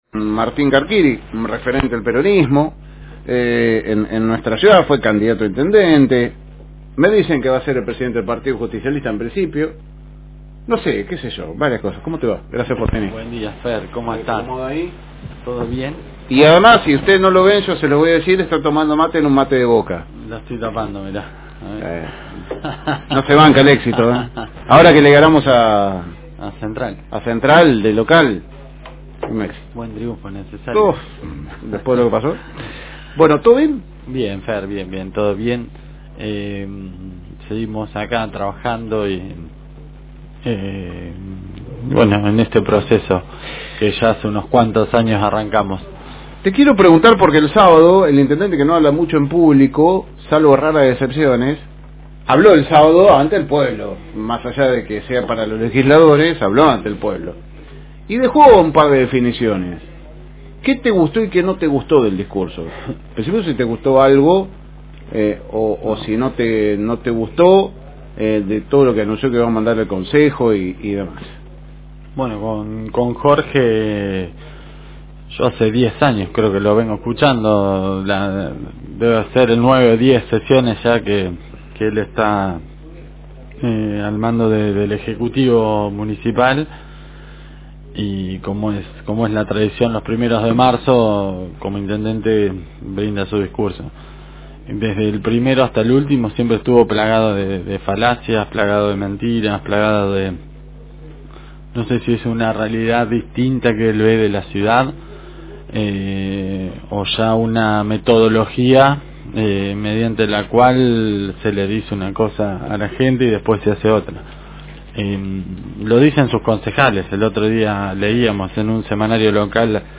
En una entrevista exclusiva